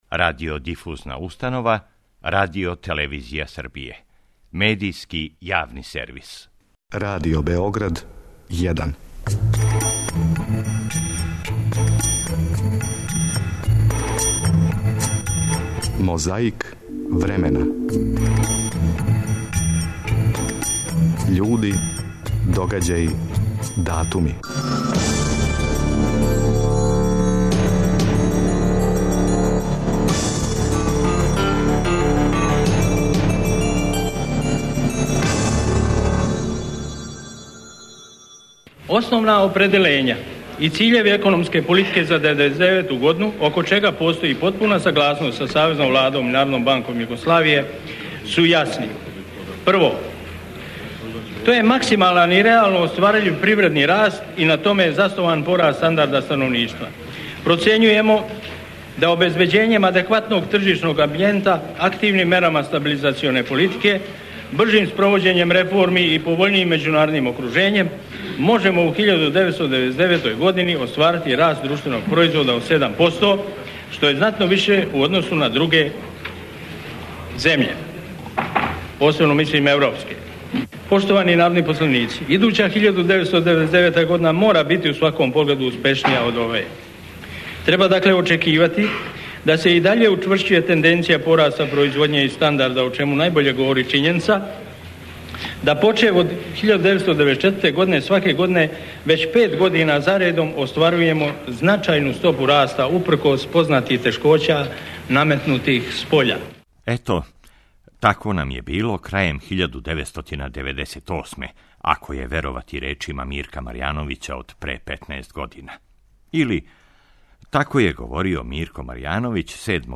Борба против пилећег памћења подсећа нас како нам је било крајем 1998. Чућемо како је говорио Мирко Марјановић 7. децембра 1998. године на тему 'Закон о буџету за 1999. годину'.
Чућете и како је, 14. децембра 1992. говорио књижевник и председник СРЈ Добрица Ћосић, приликом посете научном институту у Винчи. 8. децембра 1966. потписан је Међународни споразум о разоружавању у космосу.